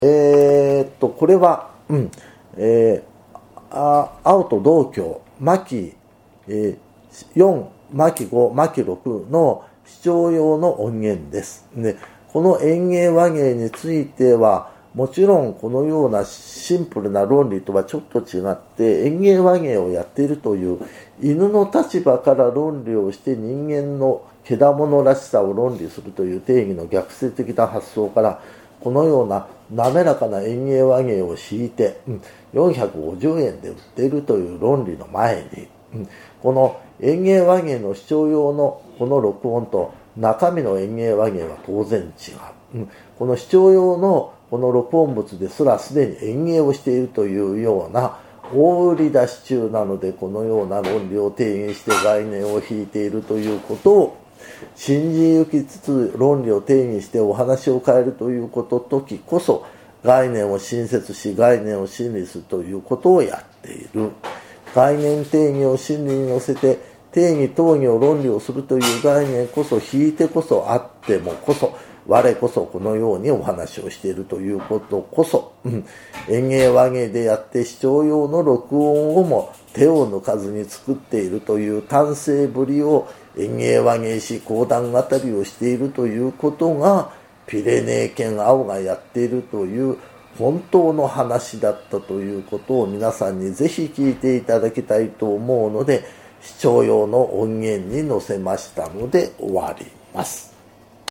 [オーディオブック] 講談 「あおと道教」 巻4・巻5・巻6